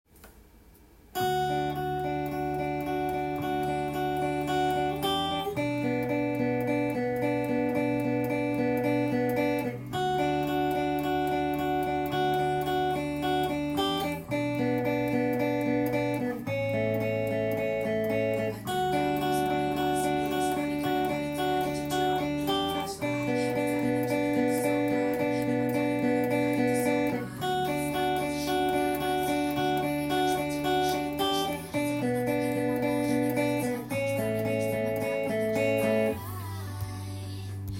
音源に合わせて譜面通り弾いてみました
この曲はギターパートがエレキギターのアルペジオから始まる
1弦～３弦のみのアルペジオになるので